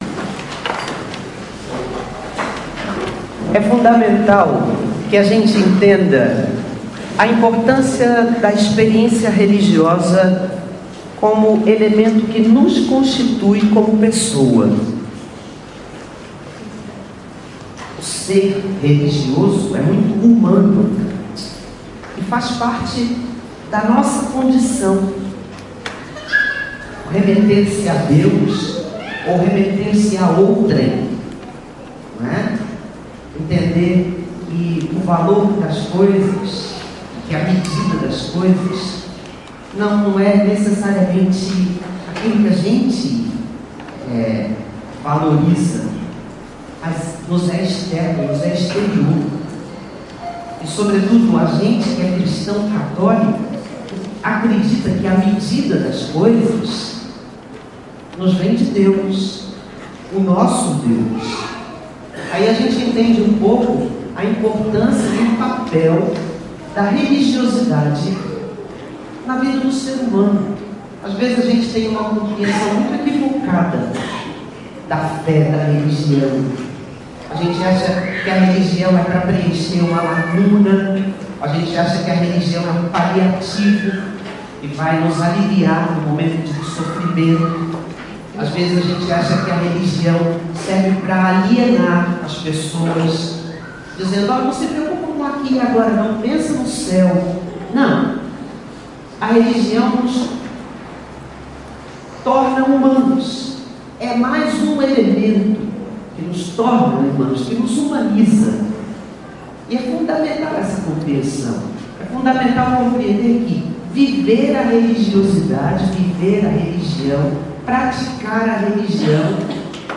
Homilia-do-26º-Domingo-do-Tempo-Comum.mp3